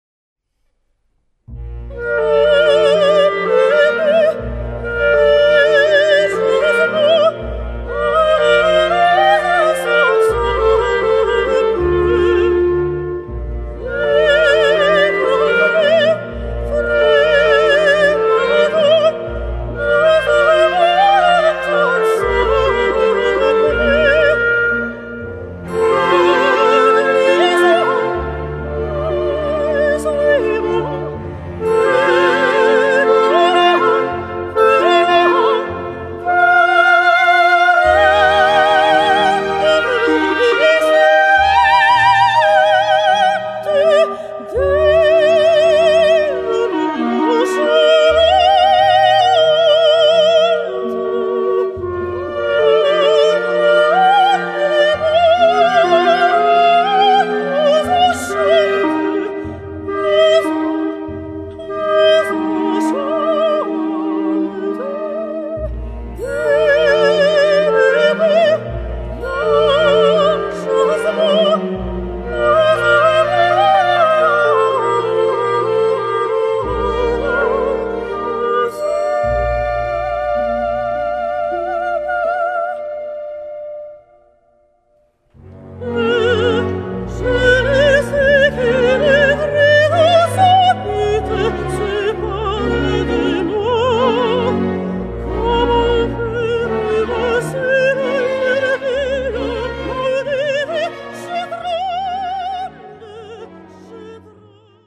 Voicing: Soprano, Clarinet and Orchestra